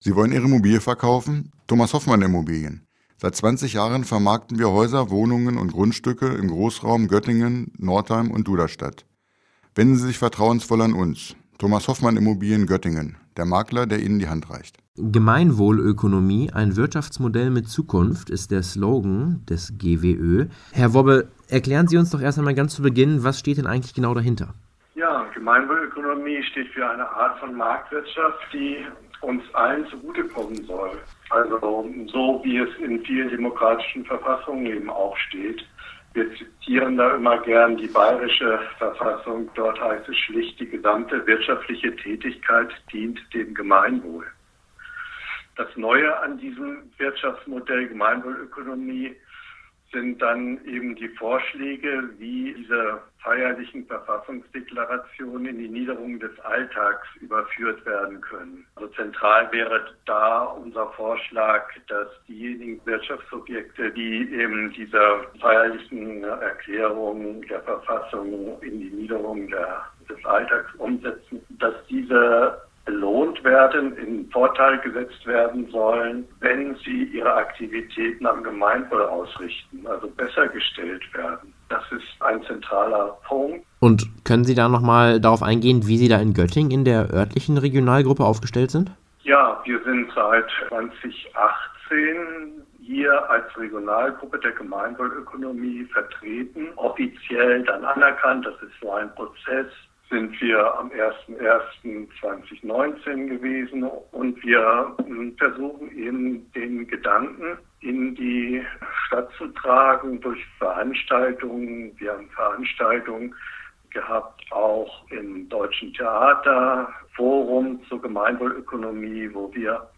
Doppel-Interview